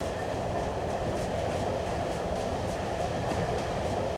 train-wheels.ogg